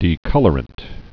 (dē-kŭlər-ənt)